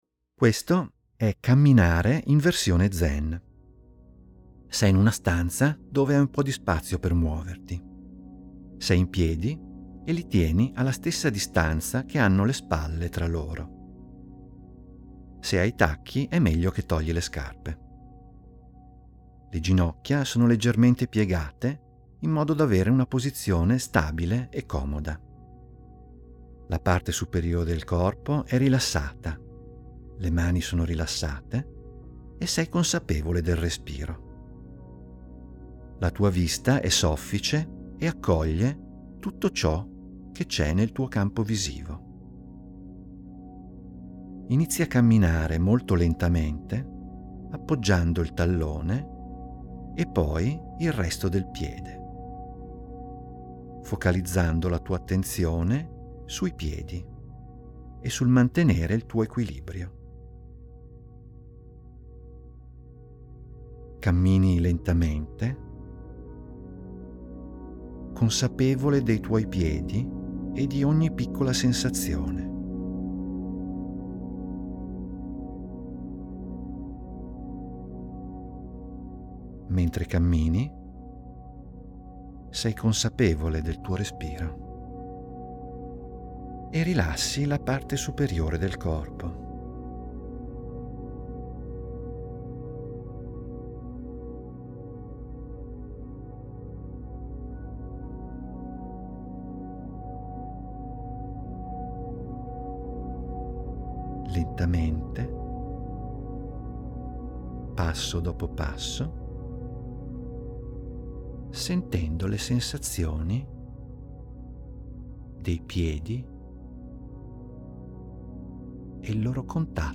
In questa sezione trovi degli audio che ti guidano a svolgere delle mini-meditazioni di due minuti.